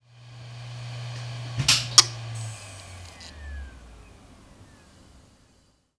Externe Harddisc - Stromunterbrechung
Dieses Geräusch bekomme ich zu hören, wenn ich mit dem Fuß unabsichtlich den Schalter an der Verteilerleiste, die sich unter dem Schreibtisch befindet, betätige und die externe Festplatte dadurch unfreiwillig ausgeschaltet wird.